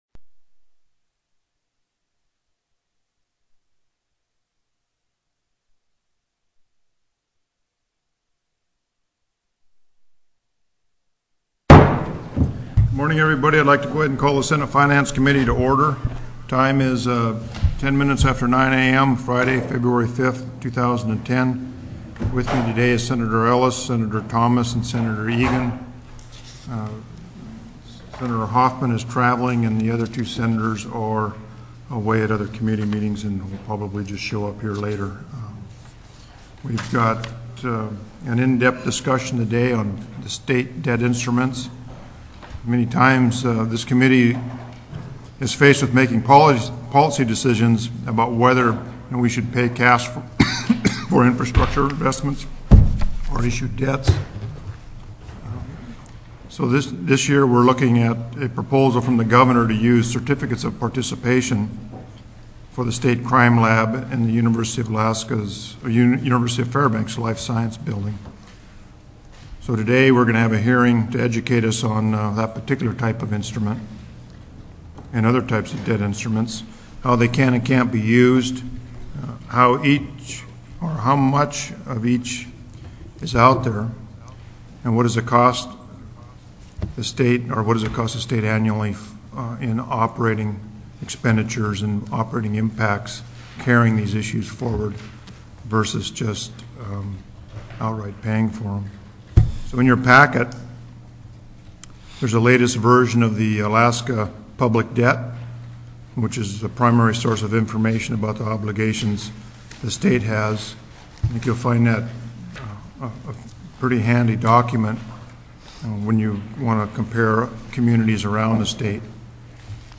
02/05/2010 09:00 AM Senate FINANCE
TELECONFERENCED